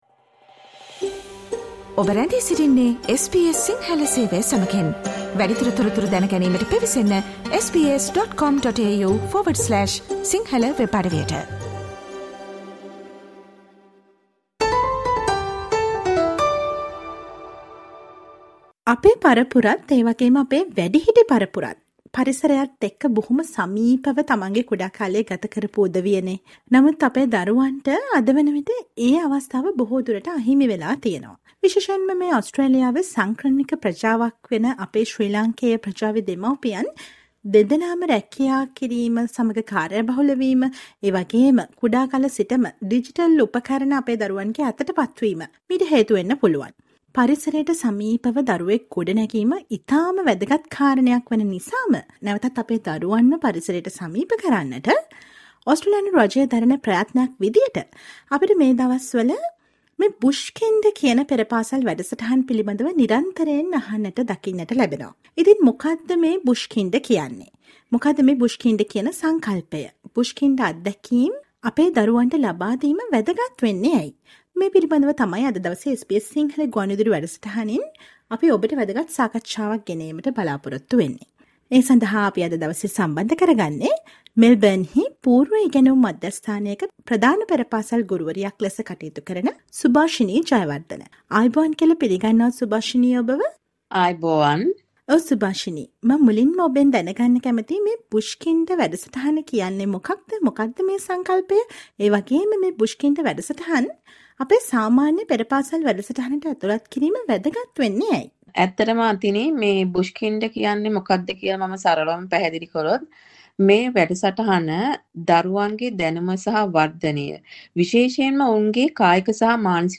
අපේ දරුවන් පරිසරයට සමීප කරන Bush kinder පෙර පාසල් වැඩසටහන් වලින් උපරිම ඵල නෙලා ගන්න ඔබට කළ හැකි දේ ගැන දැනුවත් වෙන්න සවන් දෙන්න මේ ගුවන් විදුලි විශේෂාංගයට.